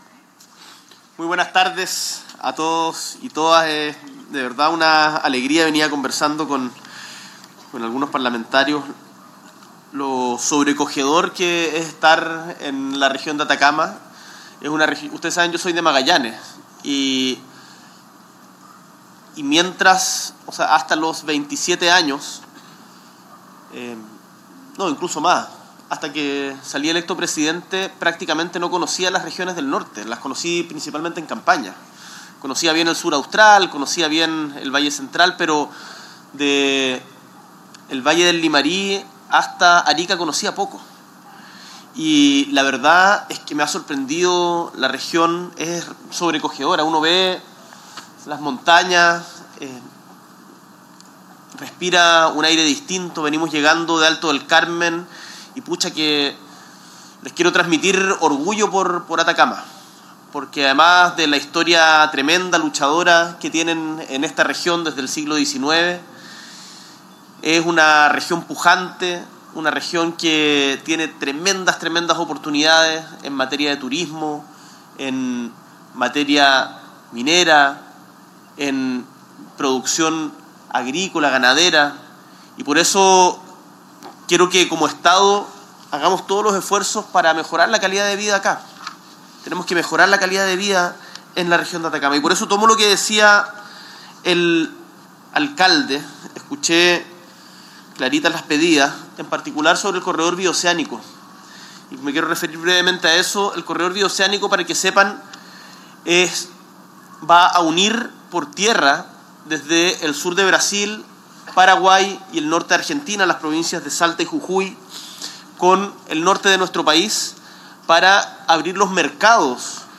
S.E. el Presidente de la República, Gabriel Boric Font, encabeza la entrega del conjunto habitacional Killari
Discurso